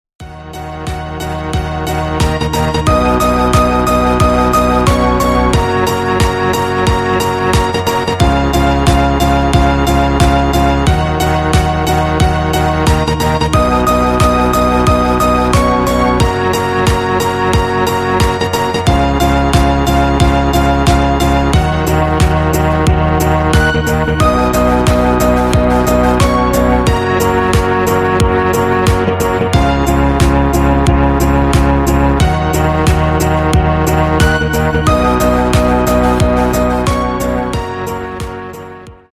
Рингтоны Без Слов
Рингтоны Ремиксы » # Рингтоны Электроника